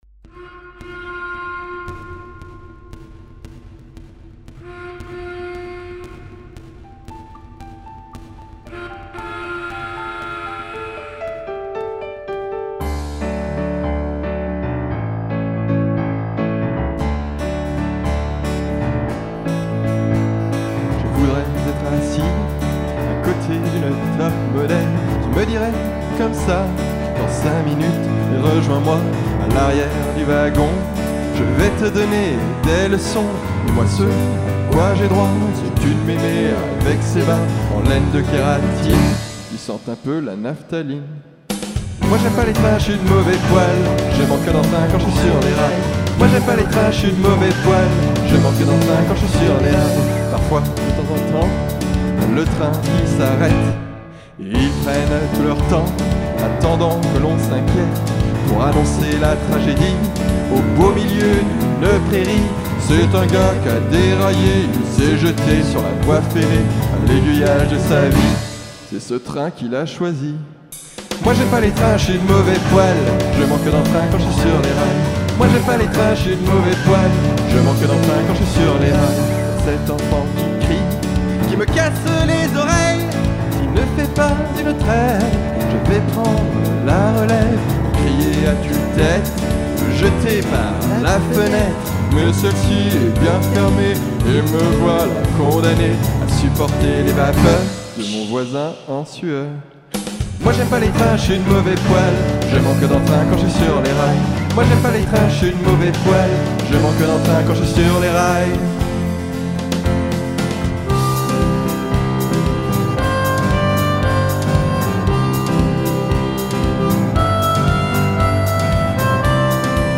La première écrite, composée et chantée par moi même.